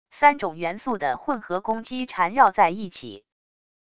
三种元素的混合攻击缠绕在一起wav音频